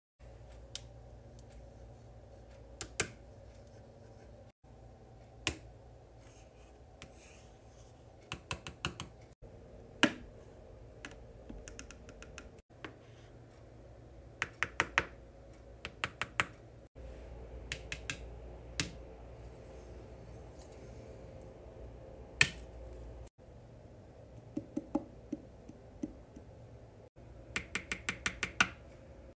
The aggressive tapping is back! sound effects free download